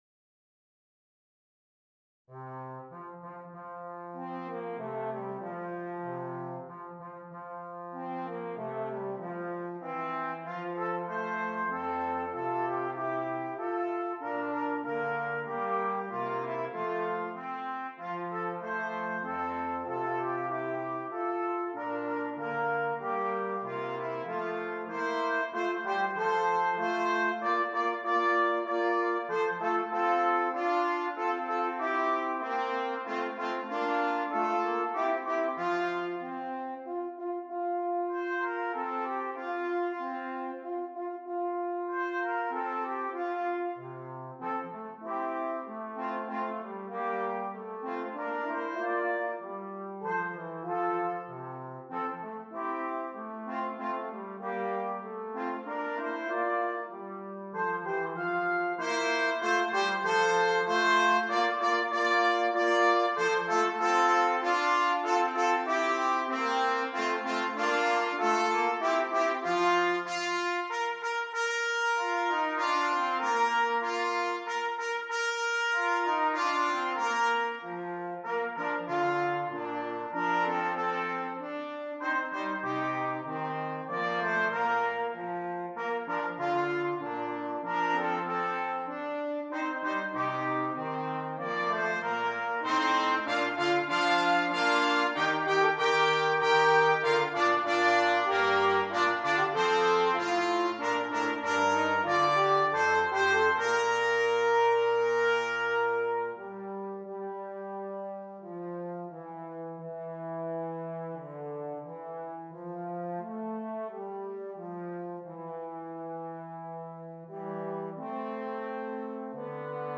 Brass Trio